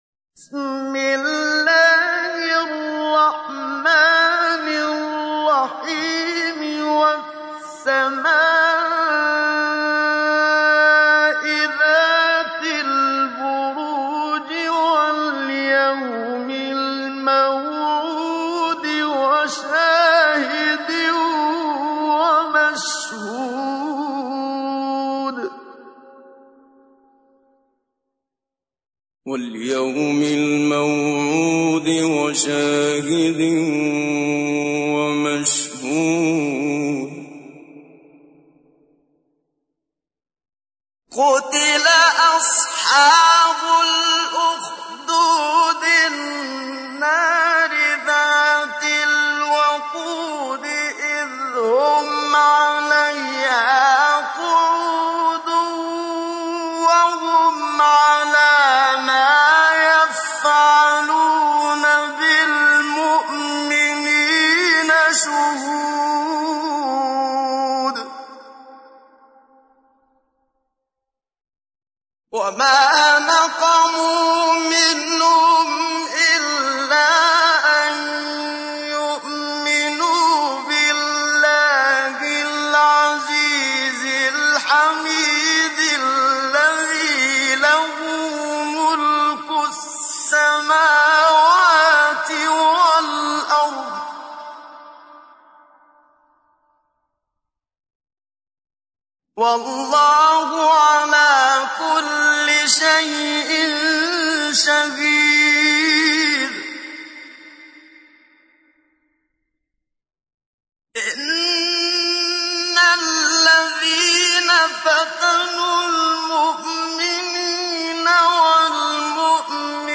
تحميل : 85. سورة البروج / القارئ محمد صديق المنشاوي / القرآن الكريم / موقع يا حسين